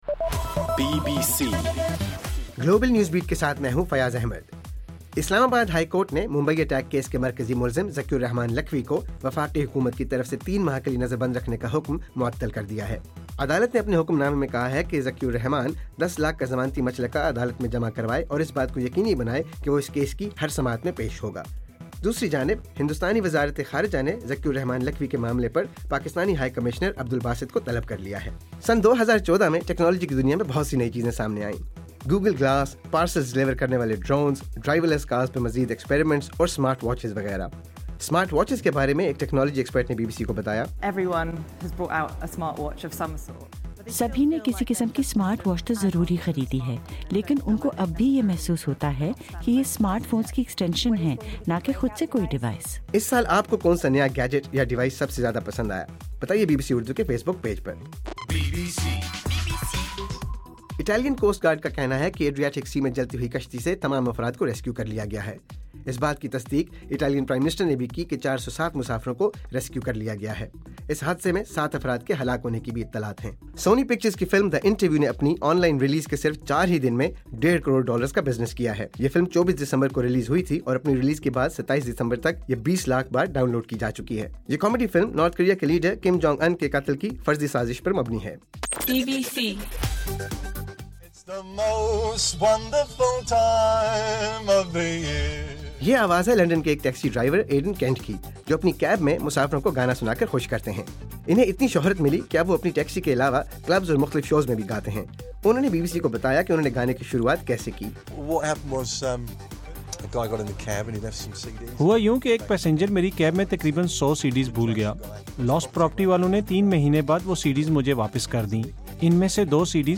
دسمبر 29: رات 10 بجے کا گلوبل نیوز بیٹ بُلیٹن